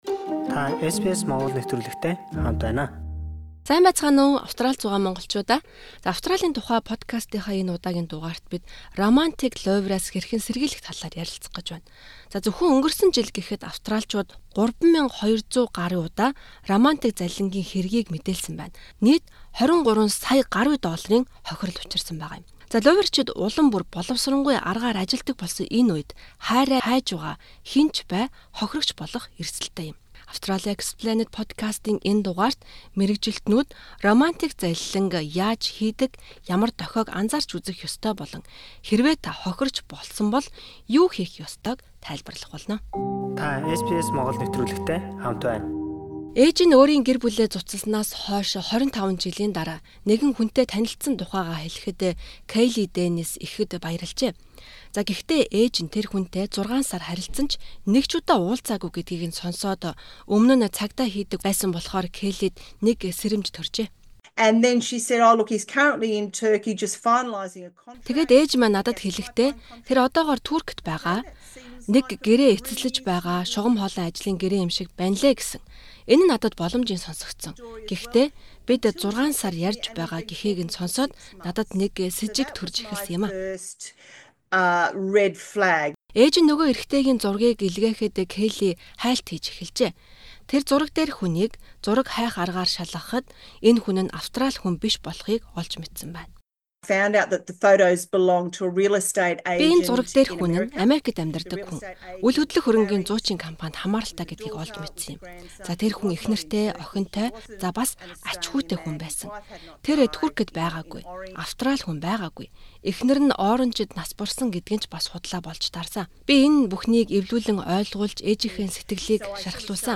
Зөвхөн өнгөрсөн онд гэхэд Австраличууд 3,200 гаруй романтик залилангийн тохиолдлыг мэдээлж, нийт 23 сая гаруй долларын хохирол амссан байна. Бид энэ чиглэлээр ажилладаг гурван мэргэжилтэнтэй ярилцаж, луйварчид хэрхэн ажиллад, анхаарах ёстой дохионууд болон хэрвээ та хохирогч болсон бол юу хийх ёстой талаар тайлбар авлаа.